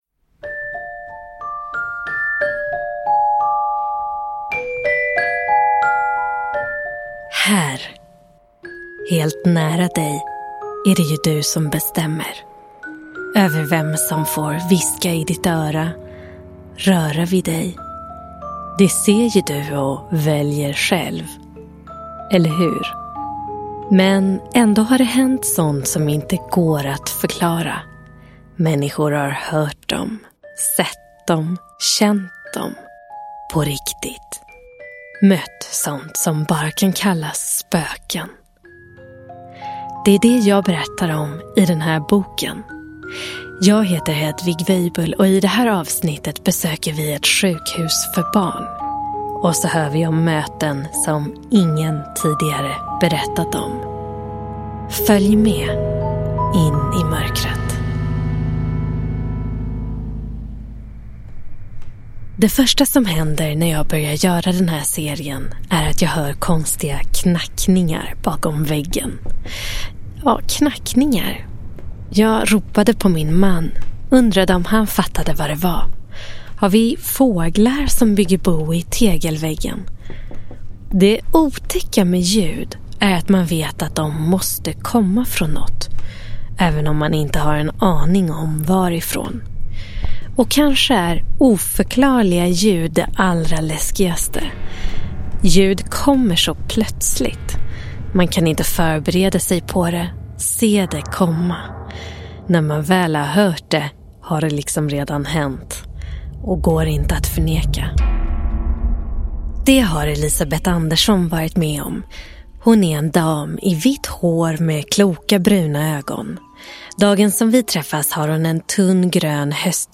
Spökhistorier på riktigt. Del 2 – Ljudbok – Laddas ner